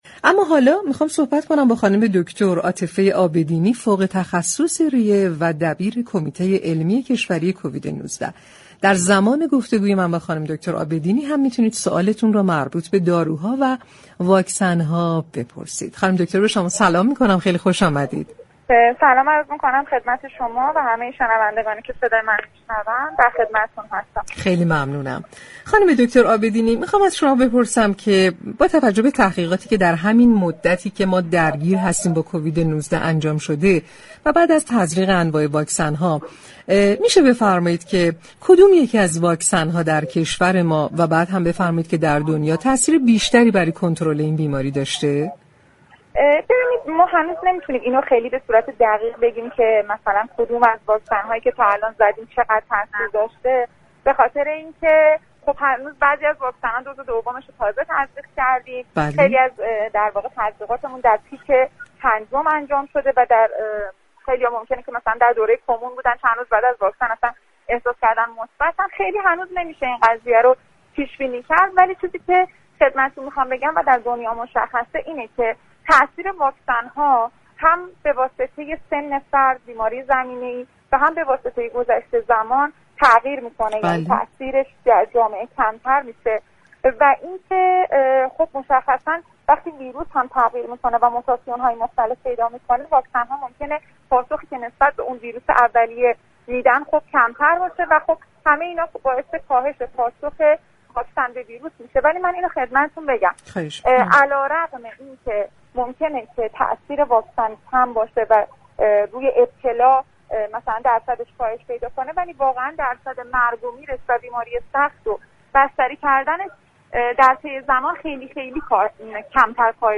در گفتگو با برنامه تهران ما سلامت